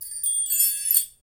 Index of /90_sSampleCDs/Roland - Rhythm Section/PRC_Asian 2/PRC_Windchimes
PRC CHIME03R.wav